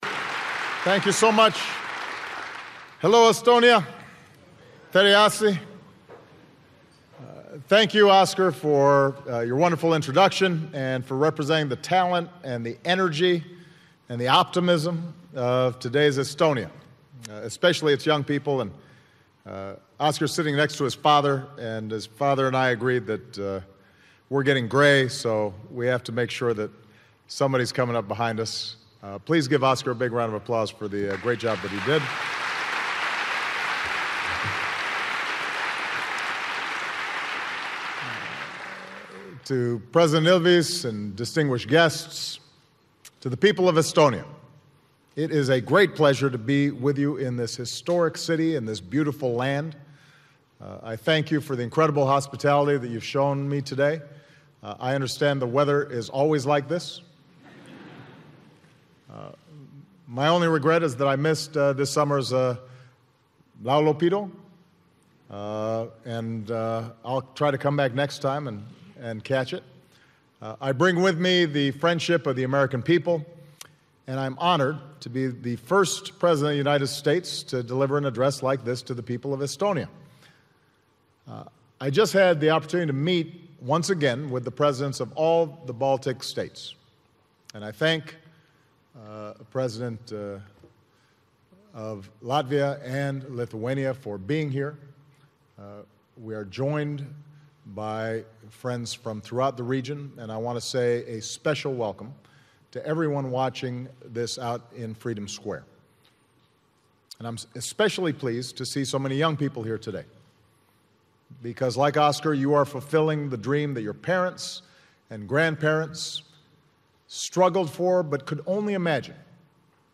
Obama addresses Nato's role in the Ukraine conflict